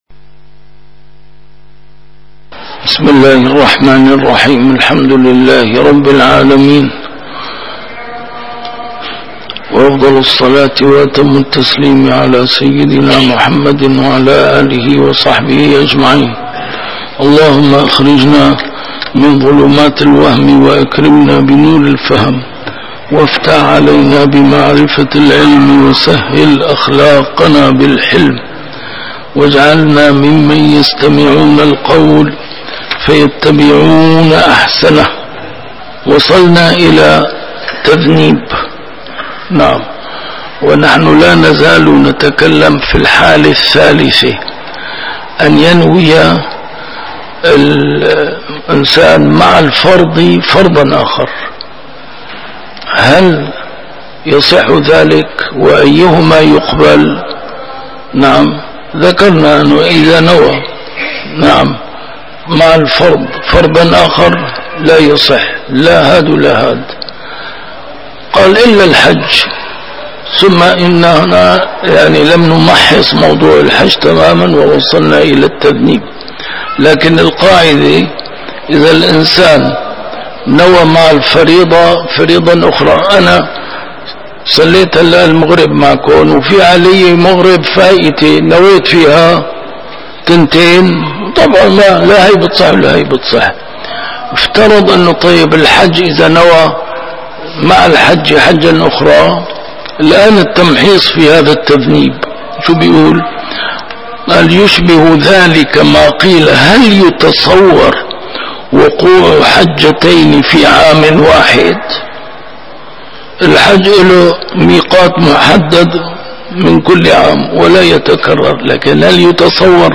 كتاب الأشباه والنظائر، الدرس الحادي عشر: ما يترتب على التمييز: الإخلاص